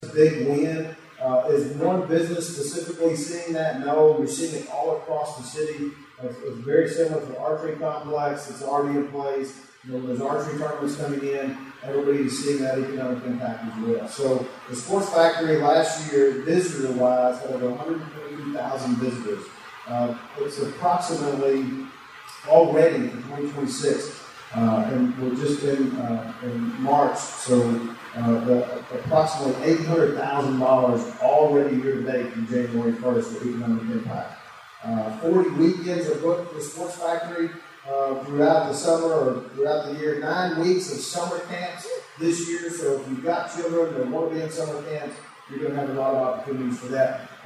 The Hopkins County Humane Society’s shelter and the economic impact of the Kentucky Sports Factory were among topics discussed last week at the State of the Cities and County event, hosted by the Hopkins County Regional Chamber of Commerce.